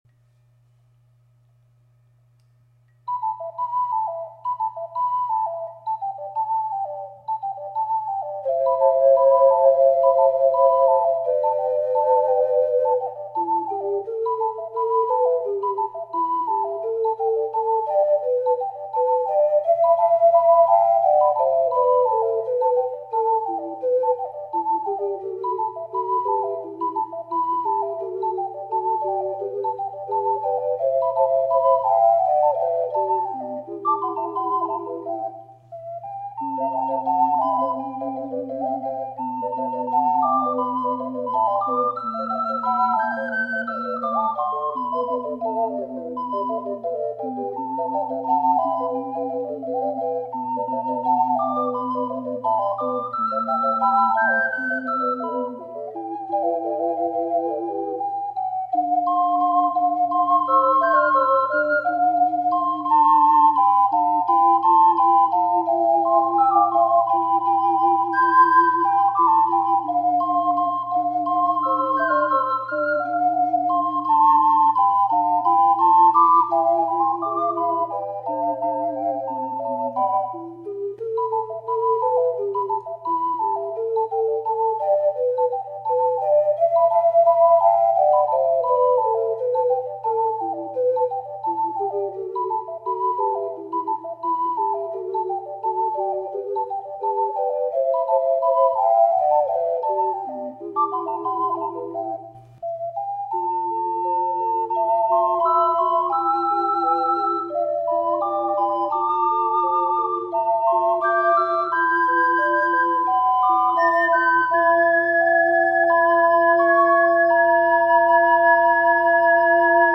④BC   楽譜より１音上げて吹きました。
指定のテンポ（♩=86）で吹き始めたら、かなり速い動きをするので焦りました。